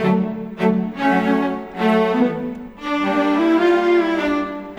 Rock-Pop 10 Cello _ Viola 02.wav